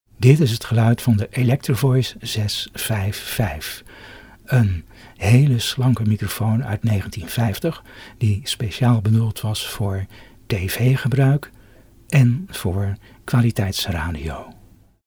Luister naar de klank van de EV 655